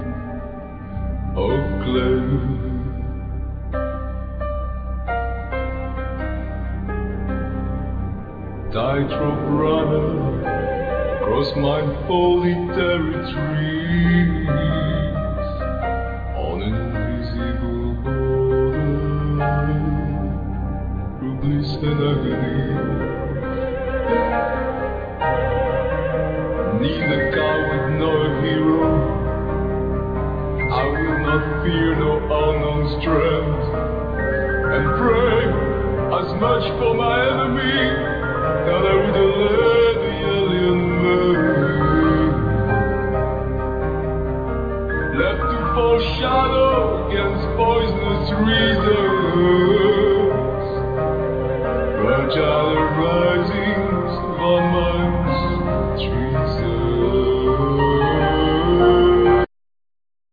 Machines,Clarinet
Machines,Vocals,Accordion
Sweet tribal drums
Trumpet
Electric bow,Slide guiter,Iron guitar
Electric guitar effects
Analog vintage synths